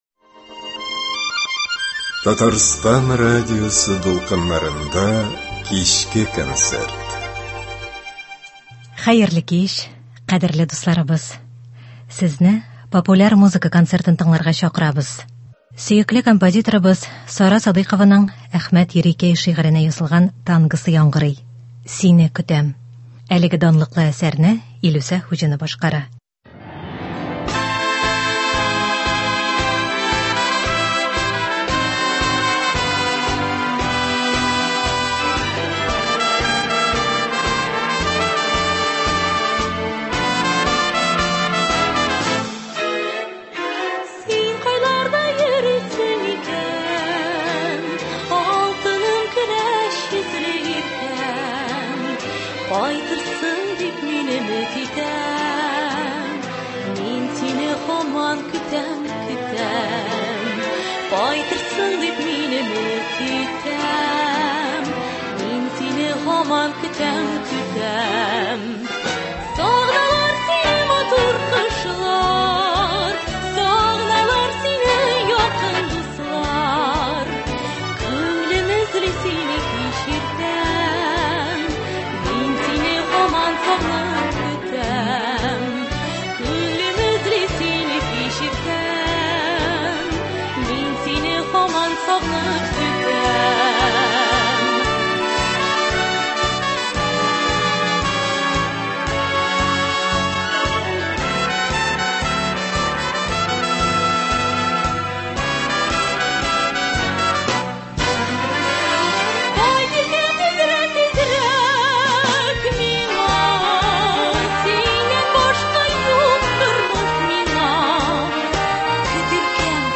Популяр музыка концерты.